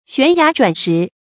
懸崖轉石 注音： ㄒㄨㄢˊ ㄧㄚˊ ㄓㄨㄢˇ ㄕㄧˊ 讀音讀法： 意思解釋： 比喻形勢發展迅猛。